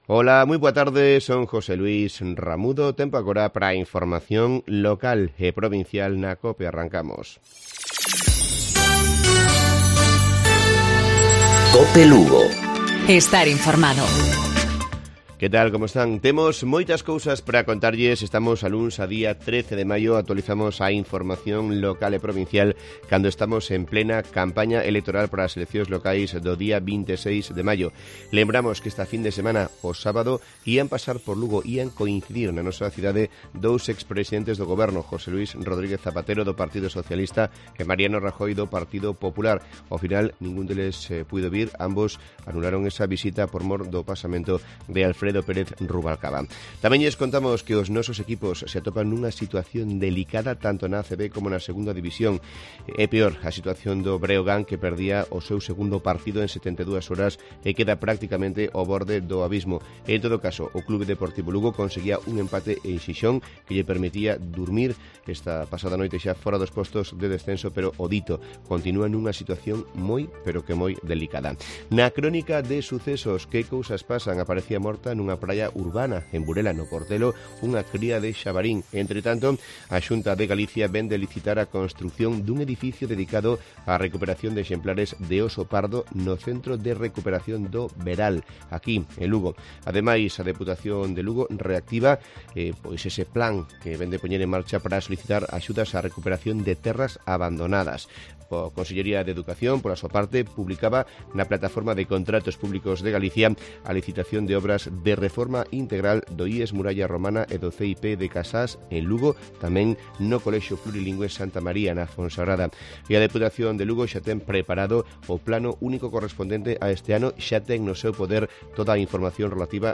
Informativo Provincial lunes 13 de mayo 12:50-13:00 horas